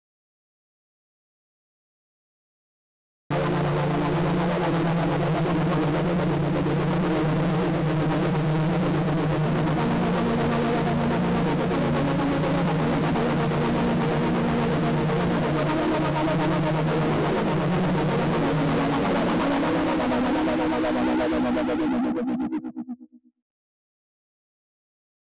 blackhole.wav